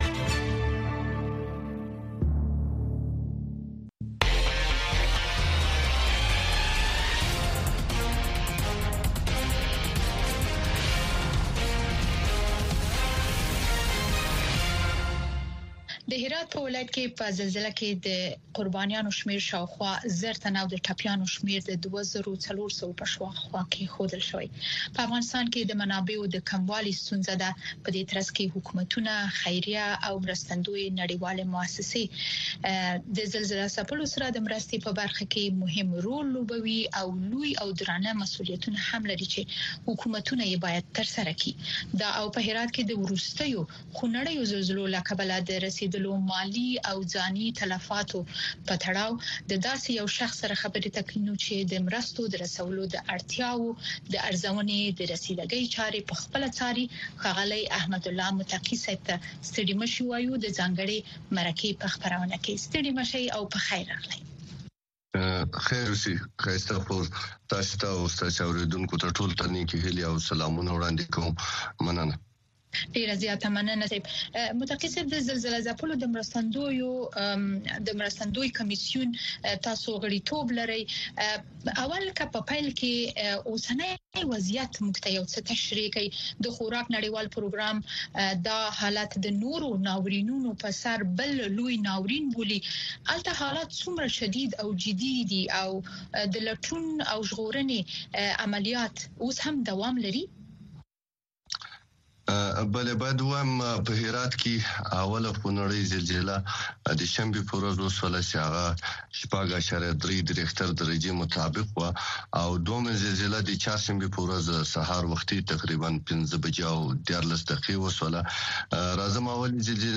ځانګړې مرکه
د افغانستان او نړۍ د تودو موضوعګانو په هکله د مسولینو، مقاماتو، کارپوهانو او څیړونکو سره ځانګړې مرکې هره چهارشنبه د ماښام ۶:۰۰ بجو څخه تر ۶:۳۰ بجو دقیقو پورې د امریکاغږ په سپوږمکۍ او ډیجیټلي خپرونو کې وګورئ او واورئ.